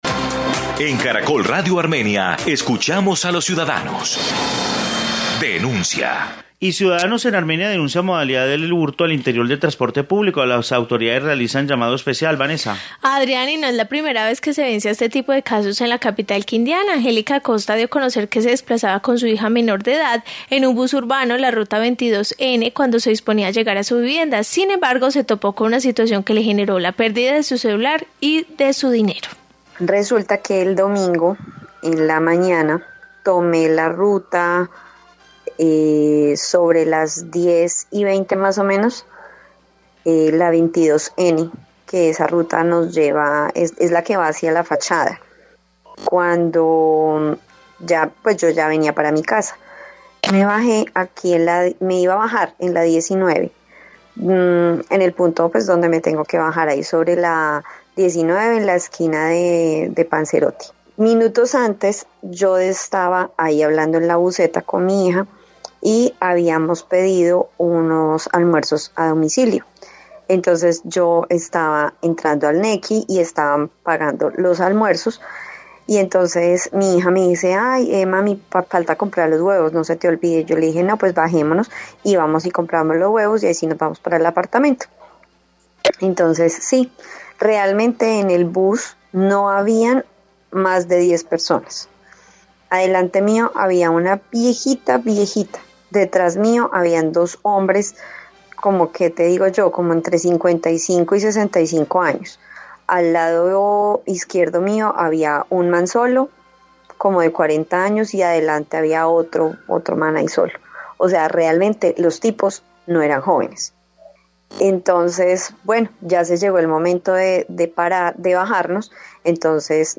Informe sobre hurto en bus